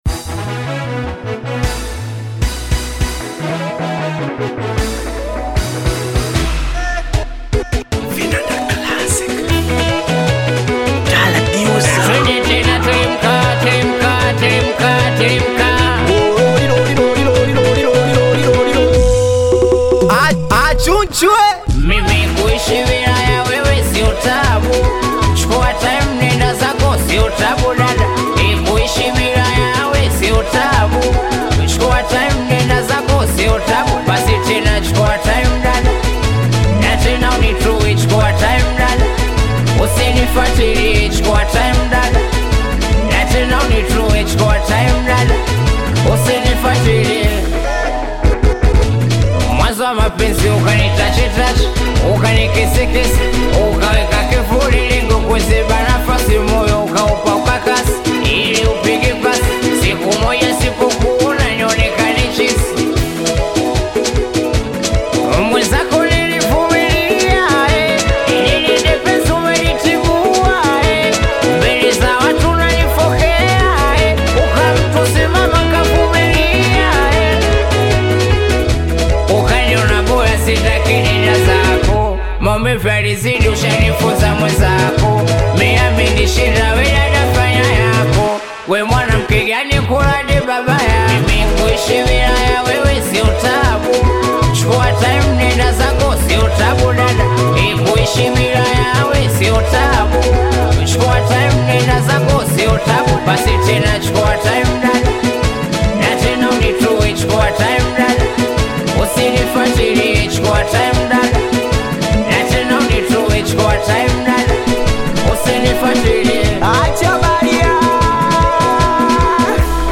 Singeli music track
Tanzanian Bongo Flava singeli